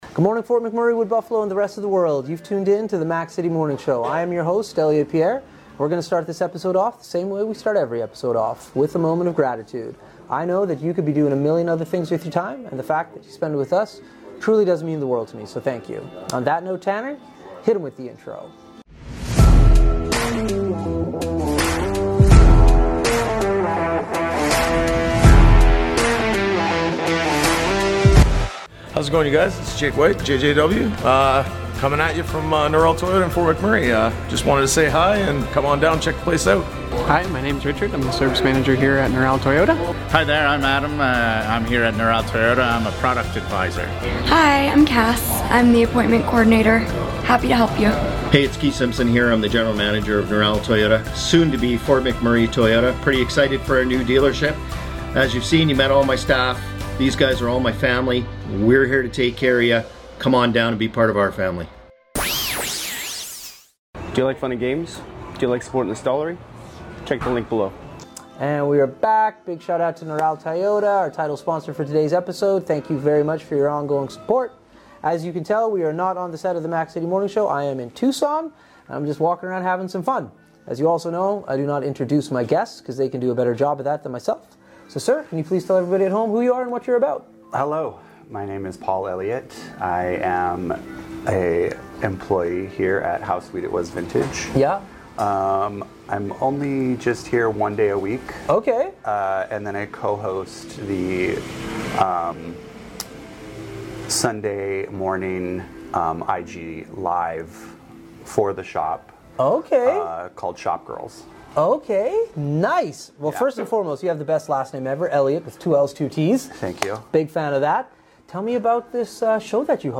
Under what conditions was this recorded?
We are on location